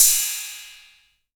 808CY_4_Tape.wav